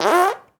fart_squirt_06.wav